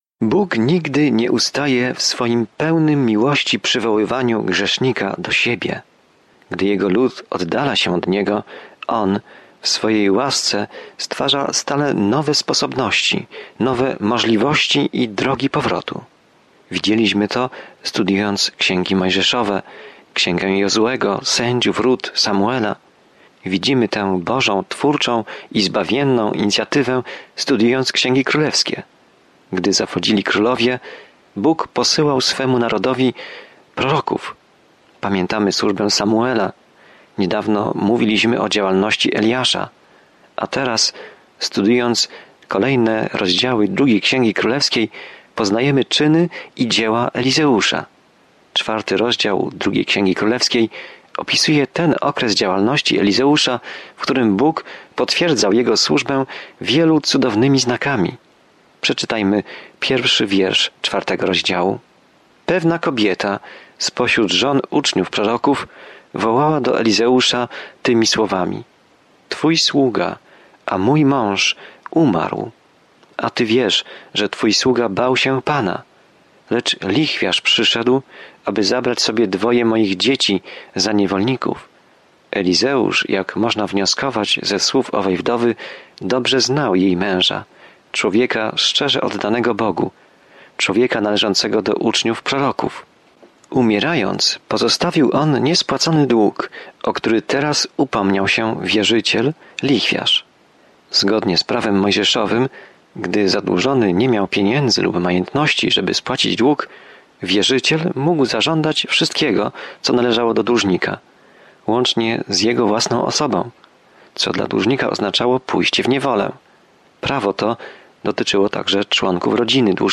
Pismo Święte 2 Królewska 4 2 Królewska 5:1-11 Dzień 2 Rozpocznij ten plan Dzień 4 O tym planie Księga Drugiej Księgi Królewskiej opisuje, jak ludzie stracili z oczu Boga i jak On o nich nigdy nie zapomniał. Codziennie podróżuj przez Księgę 2 Królów, słuchając studium audio i czytając wybrane wersety ze słowa Bożego.